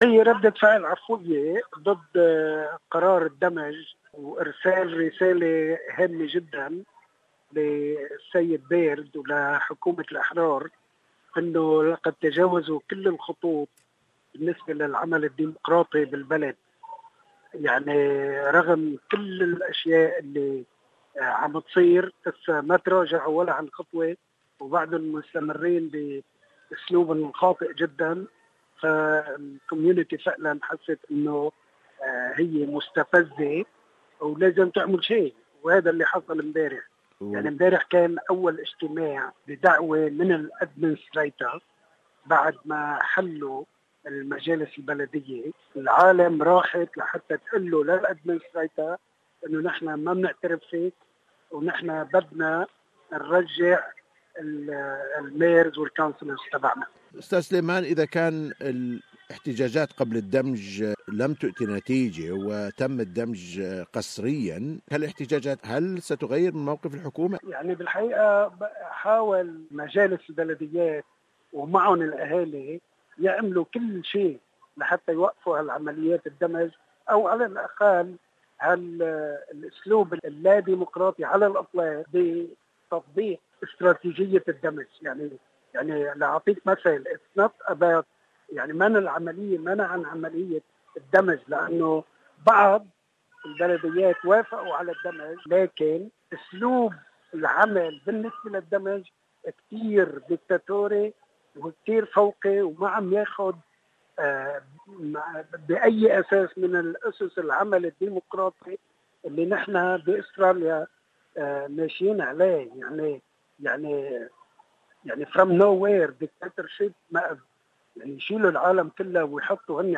Interview with former Mayor of Marrickville Council Mr. Sam Iskandar